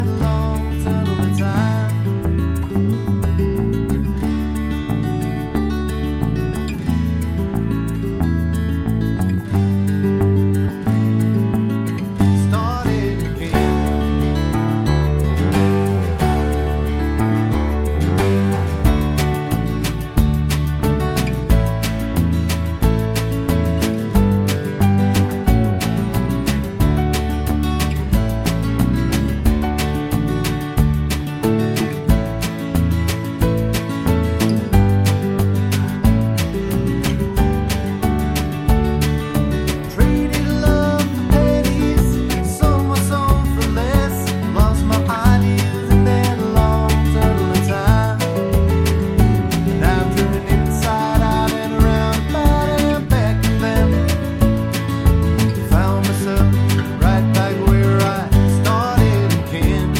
no Backing Vocals Country (Male) 3:22 Buy £1.50